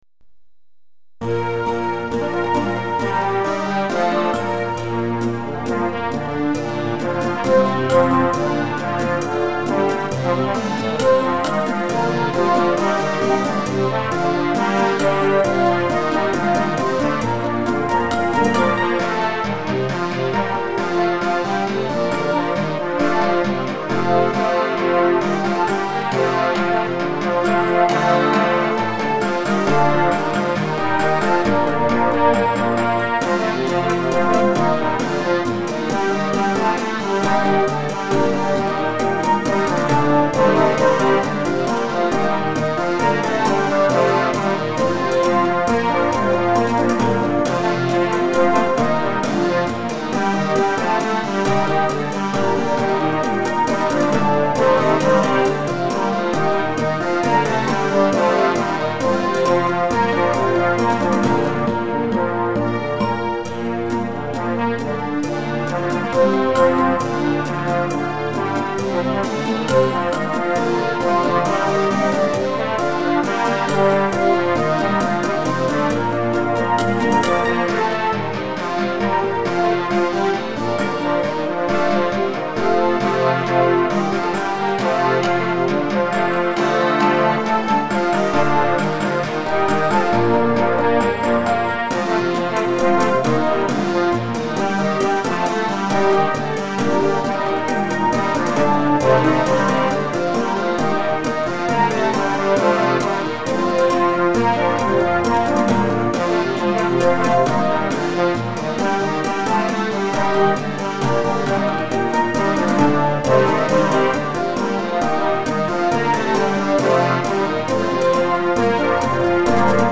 〜カラオケ版〜